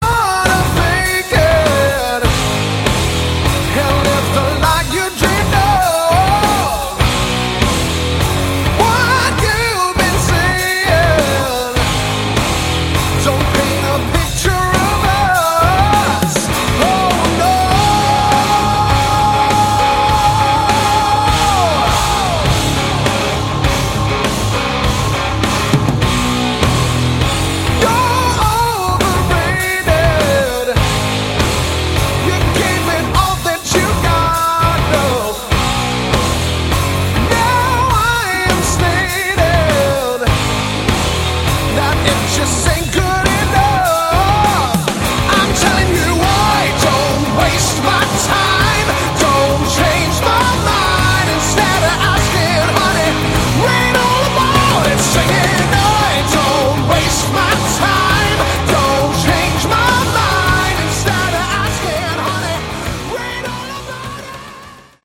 Category: Hard Rock
vocals
lead guitar
bass
drums